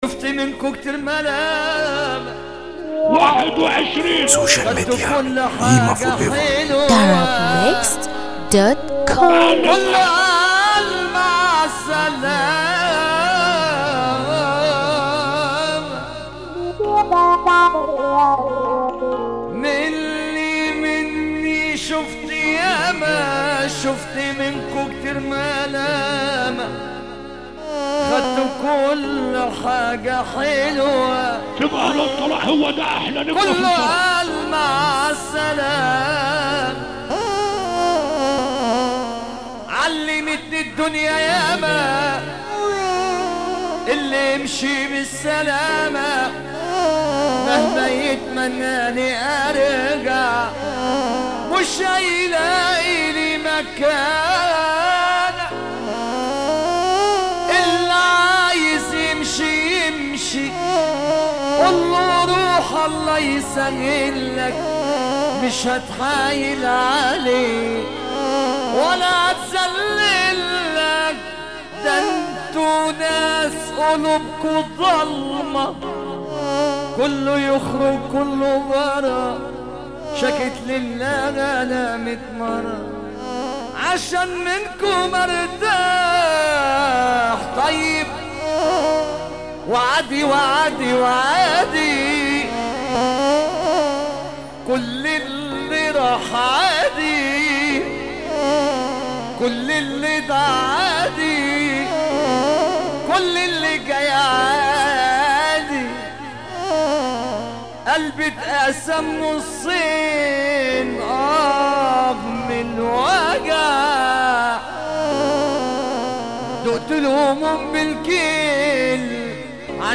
موال
حزينة جدا